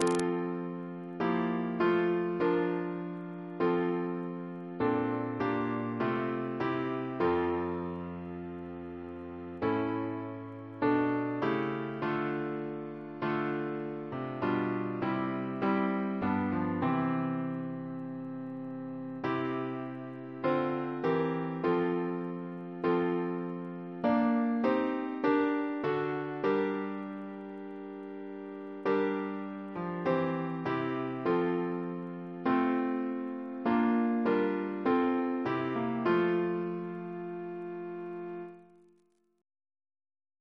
Quadruple chant in F Composer: Sir Herbert S. Oakeley (1830-1903), Professor of Music, Edinburgh Reference psalters: ACB: 16; H1982: S225; OCB: 344; RSCM: 219